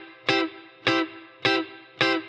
DD_TeleChop_105-Cmaj.wav